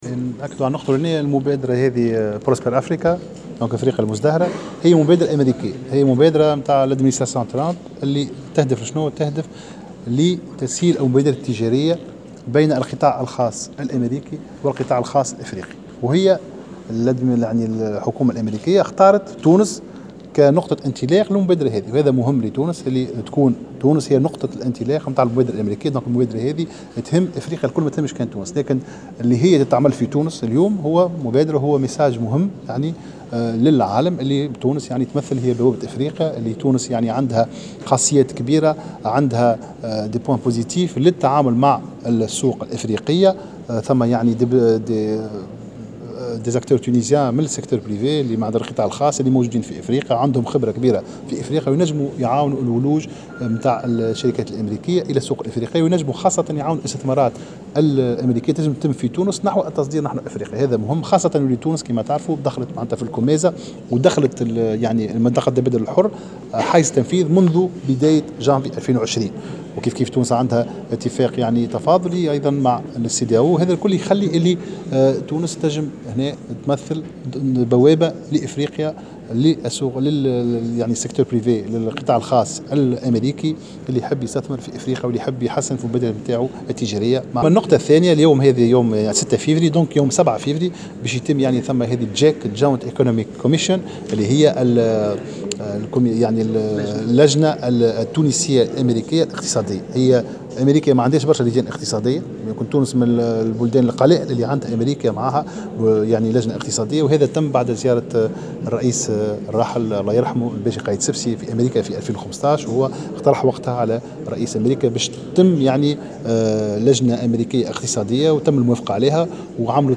أعلن وزير التجارة عمر الباهي في ندوة صحفية انتظمت صباح اليوم بمركز النهوض بالصادرات عن إطلاق المبادرة الأمريكية "ازدهار افريقيا" يوم 06 فيفري بالإضافة إلى تنظيم الدورة الثالثة للجنة الاقتصادية المشتركة التونسية الأمريكية يوم 07 فيفري.